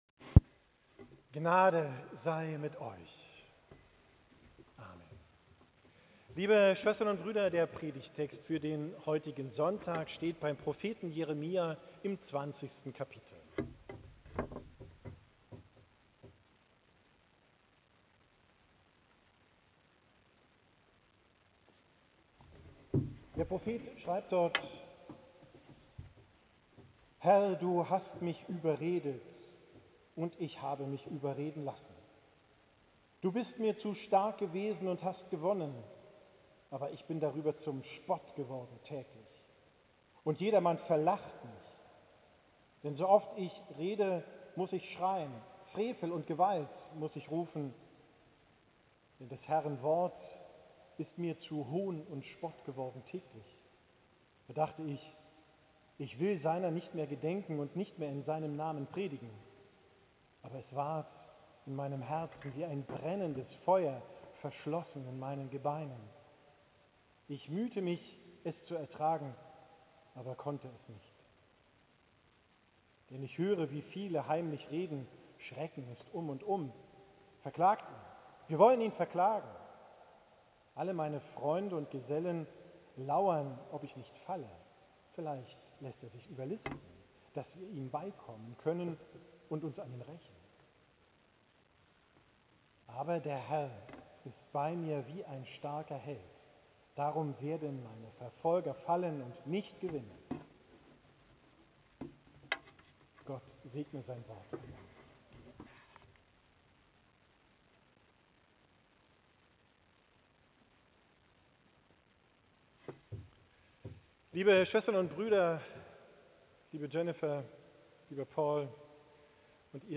Predigt am Sonntag Okuli, 23.